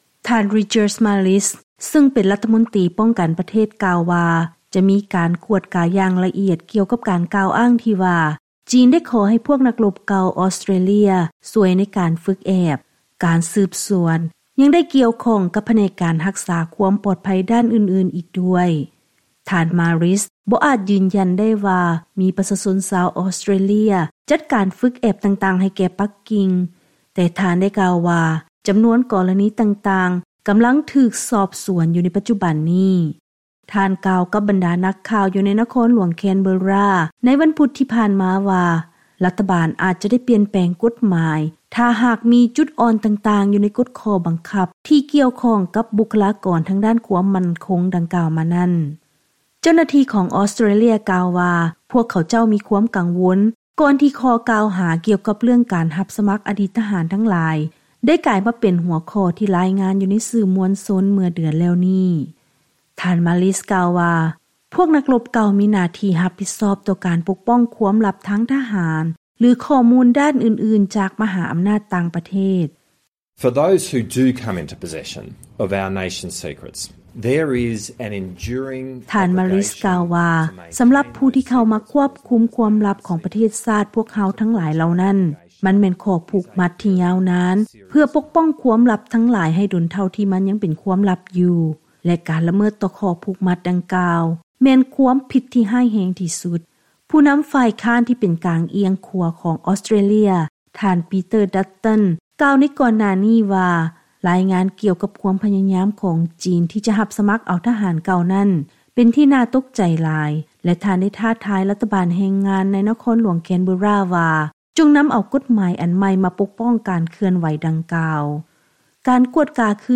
ເຊີນຮັບຟັງລາຍງານກ່ຽວກັບ ອອສເຕຣເລຍ ກໍາລັງສືບສວນກ່ຽວກັບຂໍ້ກ່າຫາທີ່ວ່າ ບັນດານັກລົບເກົ່າເຂົ້າໄປຊ່ວຍຝຶກແອບທະຫານໃຫ້ ຈີນ.